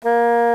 Index of /m8-backup/M8/Samples/Fairlight CMI/IIX/REEDS
OBOE1.WAV